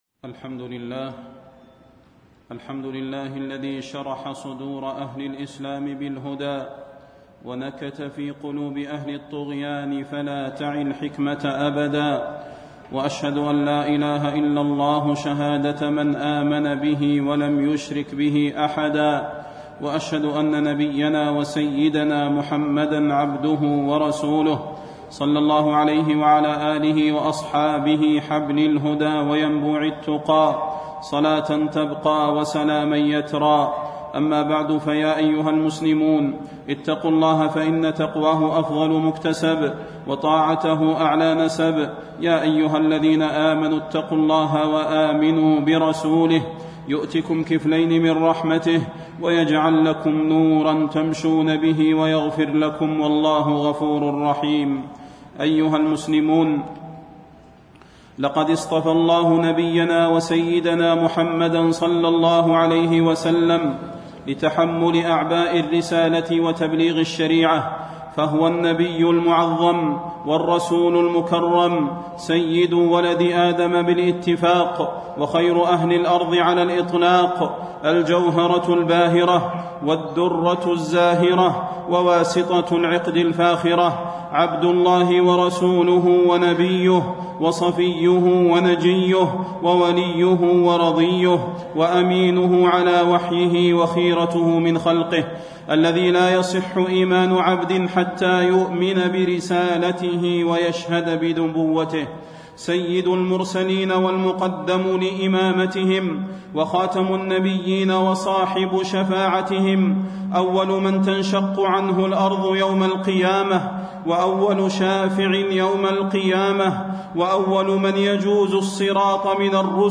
تاريخ النشر ٧ جمادى الأولى ١٤٣٣ هـ المكان: المسجد النبوي الشيخ: فضيلة الشيخ د. صلاح بن محمد البدير فضيلة الشيخ د. صلاح بن محمد البدير فضل الصلاة على النبي صلى الله عليه وسلم